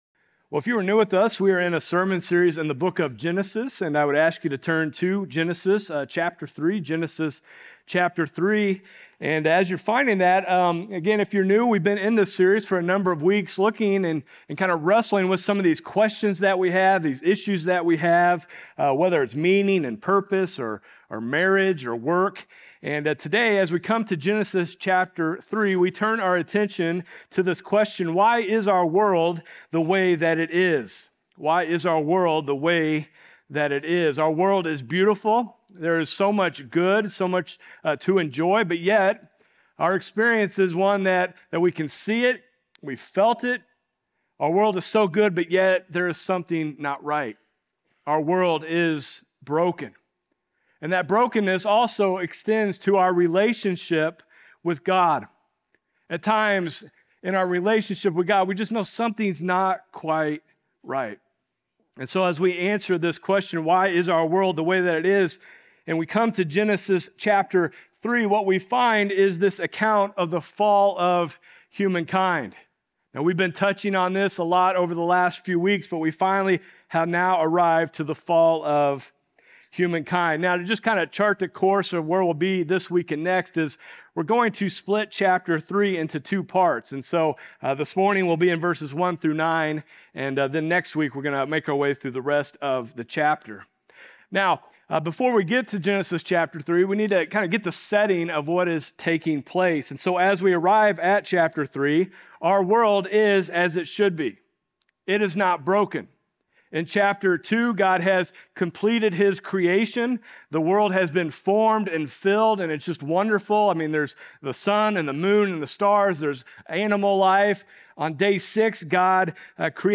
Sermons | FBC Platte City